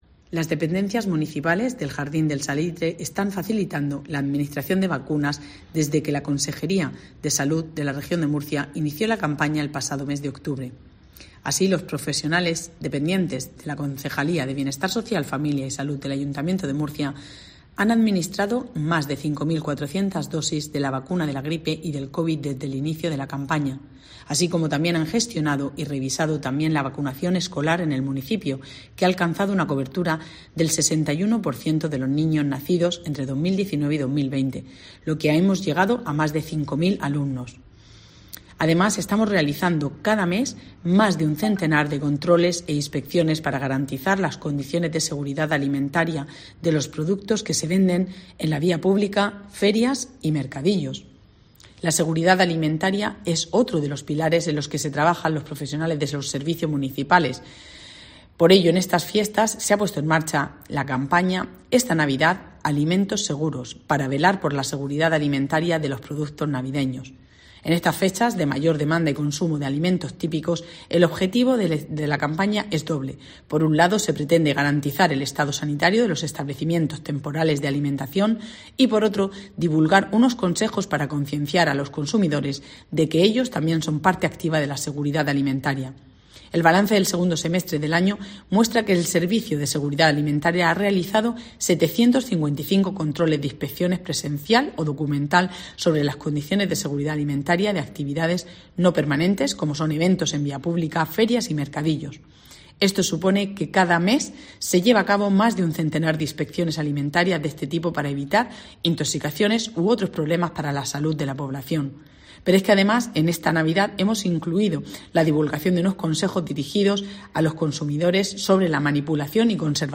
Pilar Torres, concejala de Salud del Ayuntamiento de Murcia